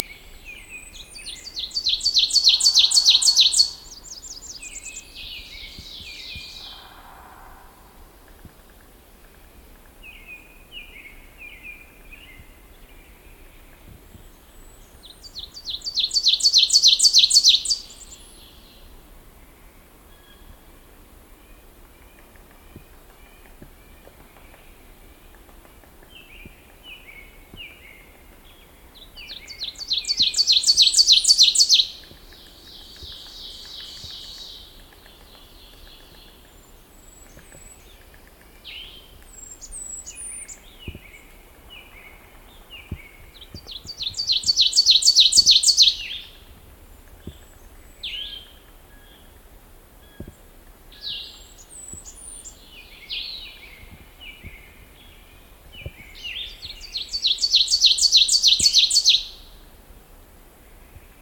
가마새의 주된 노래는 뚜렷하고 비교적 낮은 음조의 이음절 모티프가 일련으로, 약 8번 정도 멈춤 없이 반복되며 볼륨이 증가한다. 일반적으로 각 모티프의 두 번째 음절이 날카롭게 강조된다: "''chur-tee’ chur-tee’ chur-tee’ chur-tee’ chur-TEE chur-TEE chur-TEE!''"
가마새의 노래, 미네소타 주에서 녹음